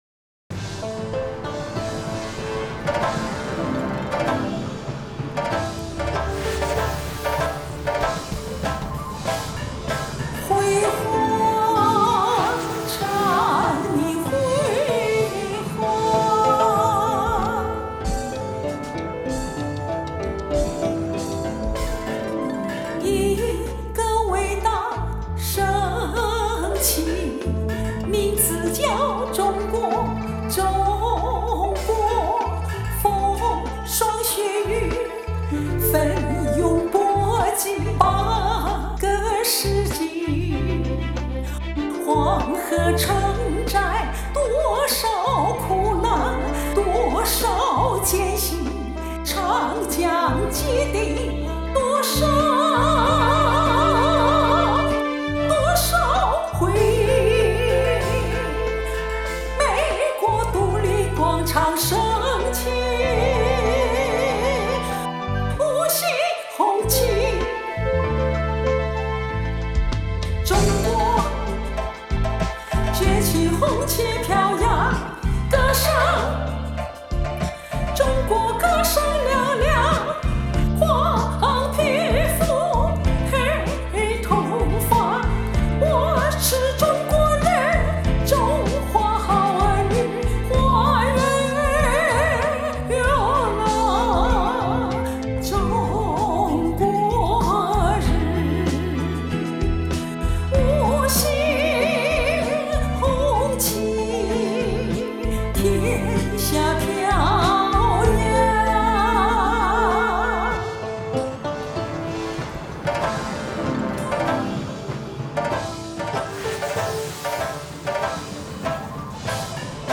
气势磅礴、旋律优美